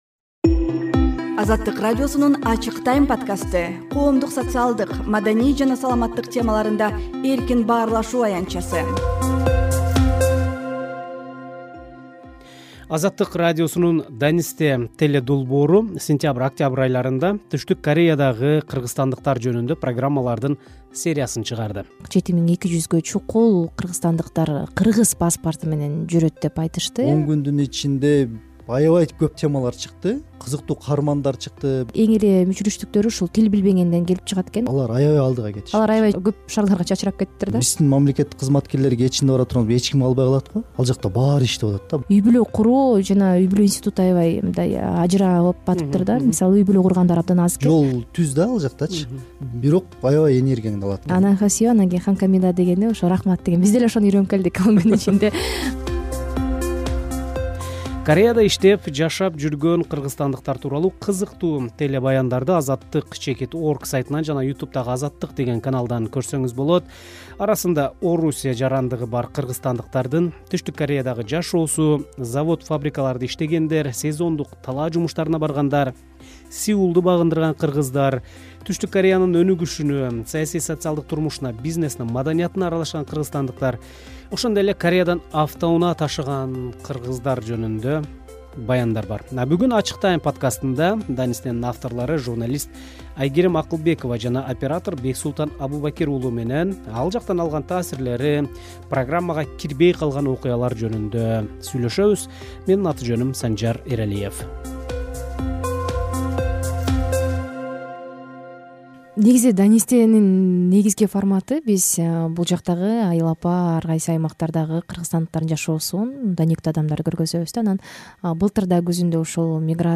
“Азаттык” радиосунун “Данисте” телепрограммасы сентябрь-октябрь айларында Түштүк Кореядагы кыргызстандыктар жөнүндө программалардын сериясын чыгарды. Кабарчыларыбыз ал жакта көргөн-билгендерин, “Данистеде” айтылбай калган маалыматтарды “Ачык Time” подкастында бөлүшүшөт.